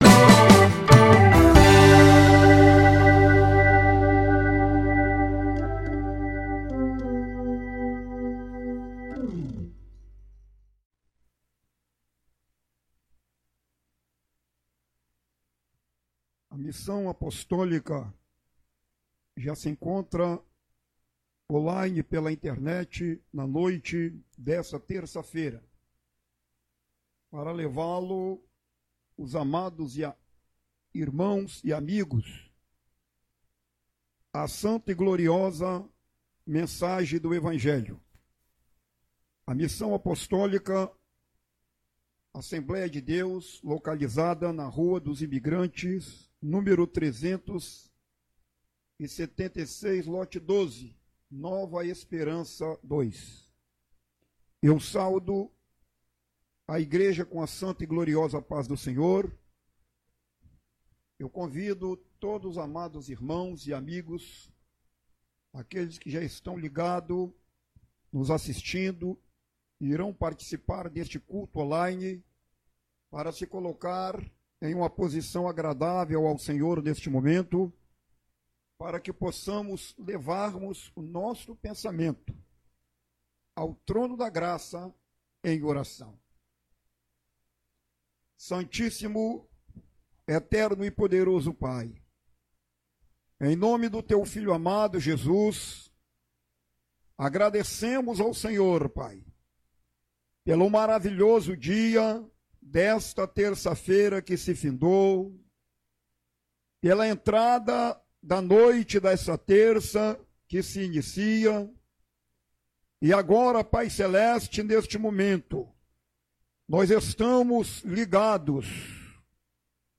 Culto-da-Palavra-Fugindo-da-tentação-1.mp3